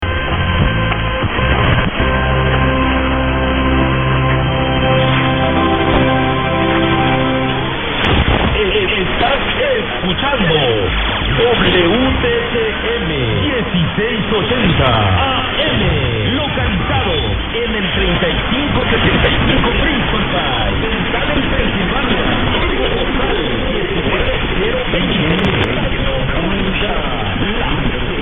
I have also included an ID from the accented OM on 1650 from WHKT. Under certain condx, it doesn't really sound like that.